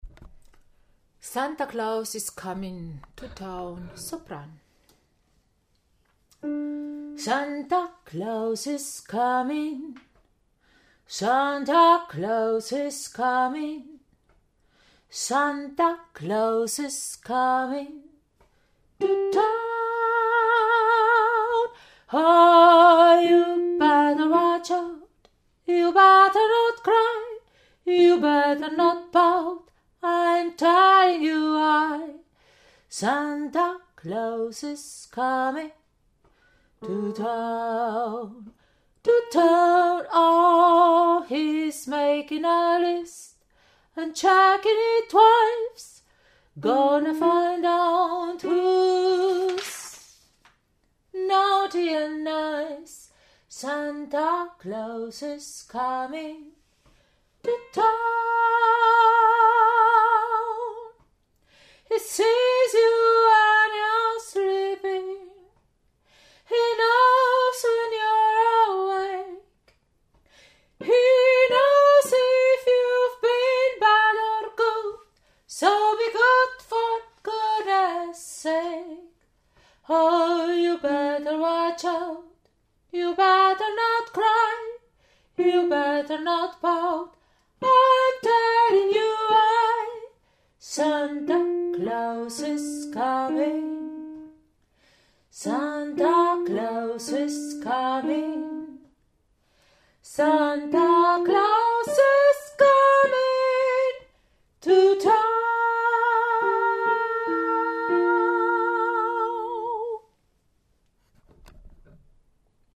Santa Claus is comin Sopran
Santa-Claus-is-comin-sopran.mp3